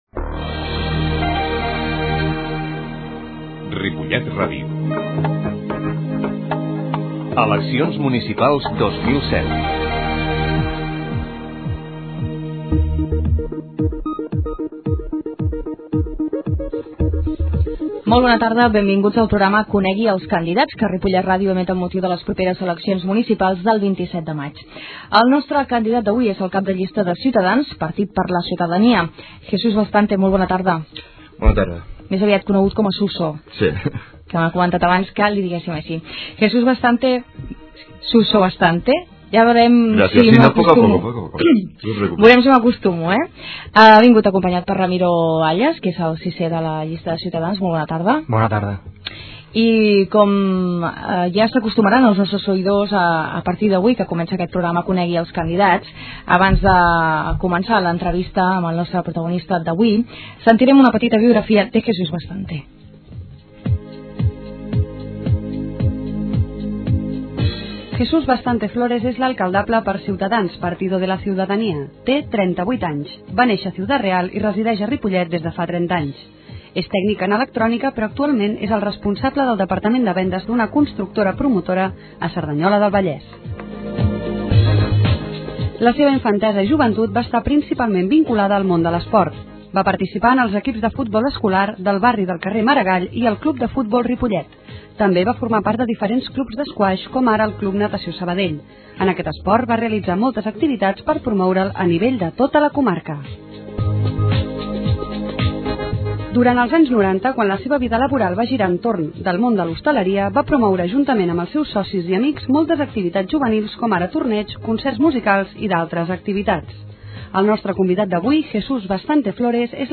Descarregar arxiu ripollet-politica-eleccions-ciutadans-entrevista-candidat-web-160507.mp3
S'ha baixat la qualitat del so de l'arxiu per tal de reduir el temps de desc�rrega.